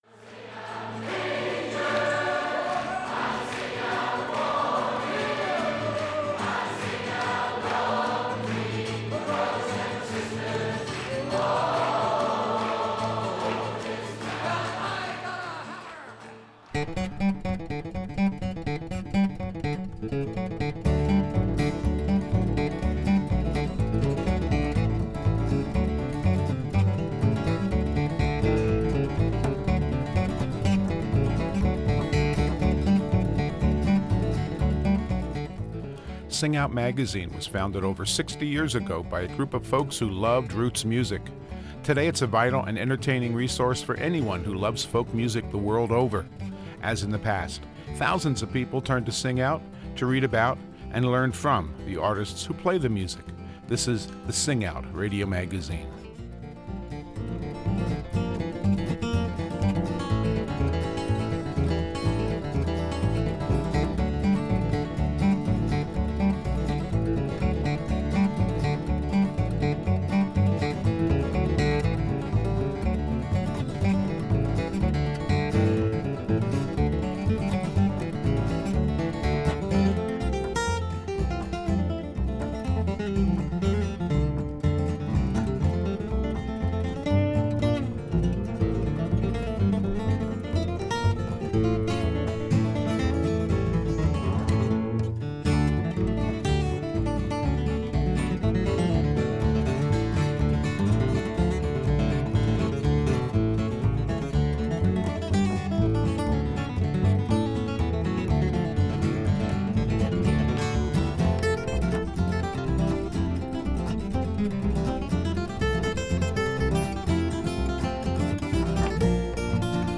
Stories are an important part of any time of year, and with this program we begin a two-part presentation of singers and song-writers who tell wonderful stories with their songs.